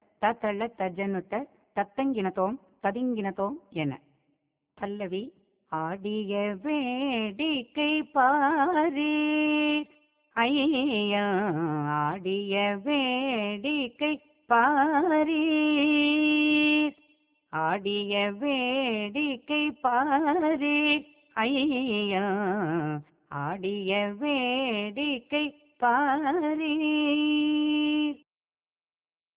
சரணத்தின் கடைசிப் பகுதி
இவ்வாறு அமையும் கீர்த்தனைகள் "தாண்டவக் கீர்த்தனைகள்" எனப்படும்.
சாருகேசி
மிச்ரஜம்பை